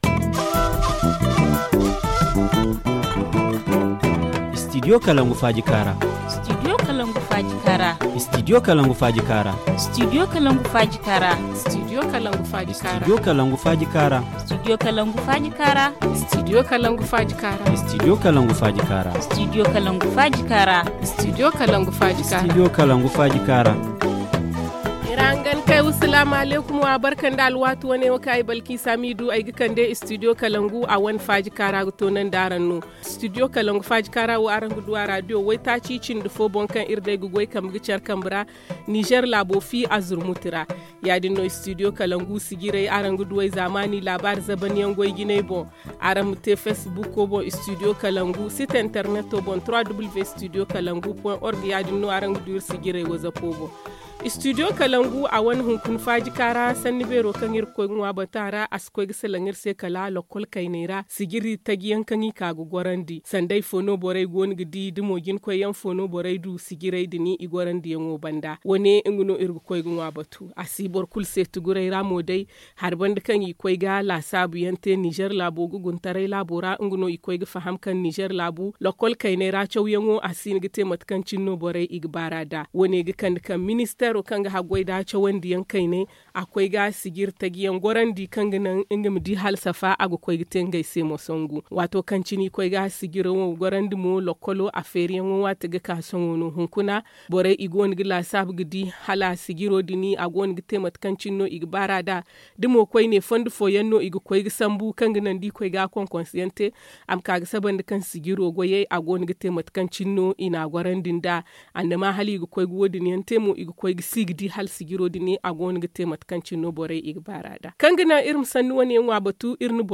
Le forum en zarma